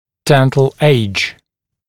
[‘dent(ə)l eɪʤ][‘дэнт(э)л эйдж]возраст развития зубов